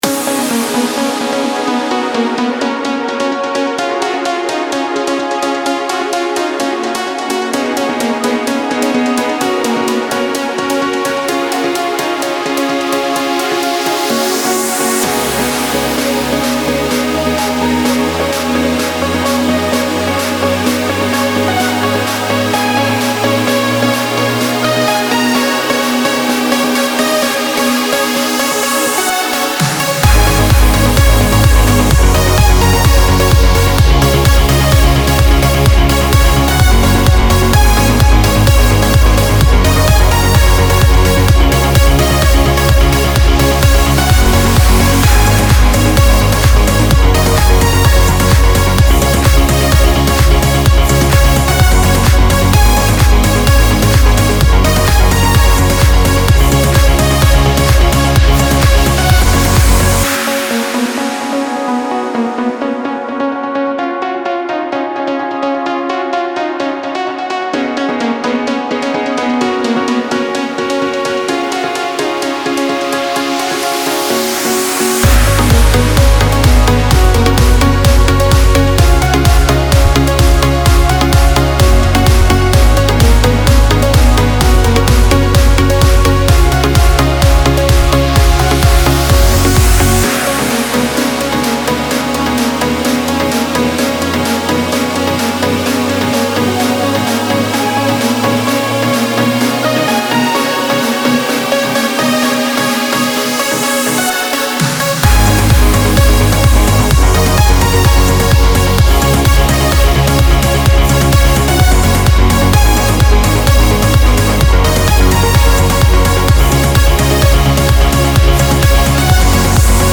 הנה דוגמא של מנגינה שהורדתי להתעמלות